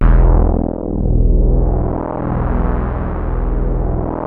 JUP.8 E2   2.wav